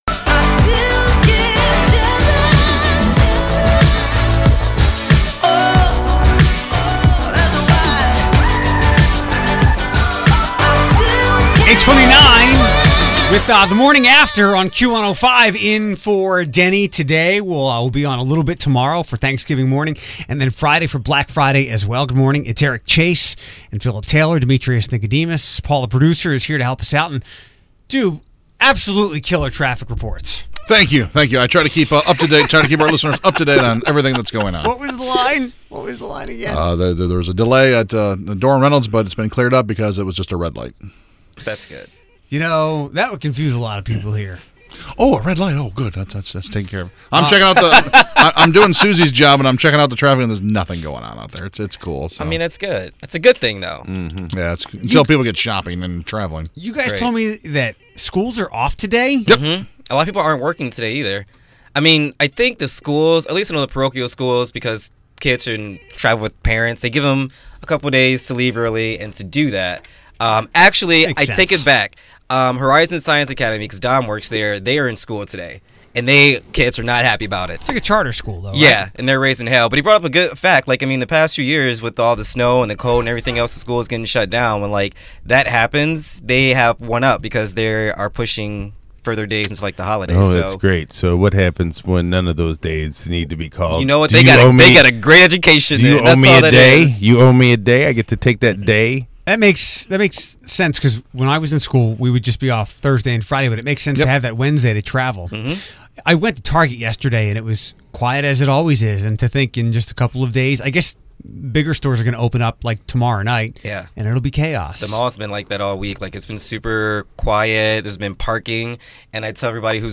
You have five seconds to name three things or the buzzer clobbers you!